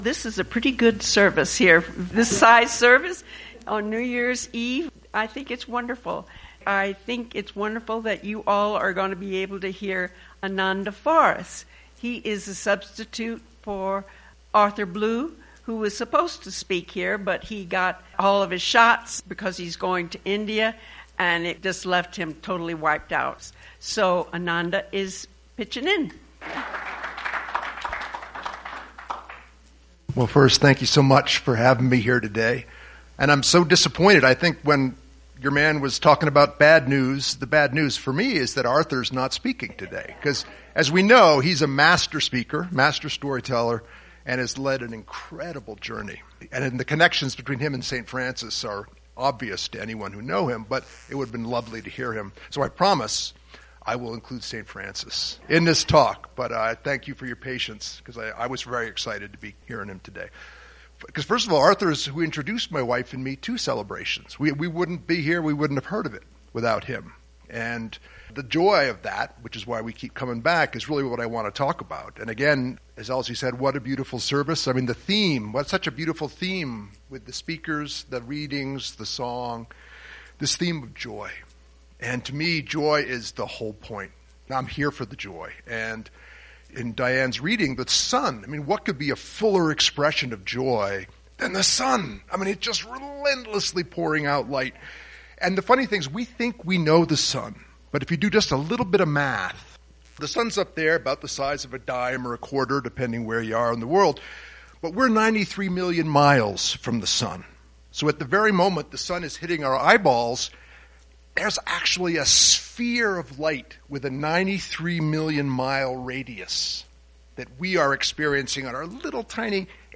Talk given at the Celebration in Santa Fe, NM in December, 2023 about JOY!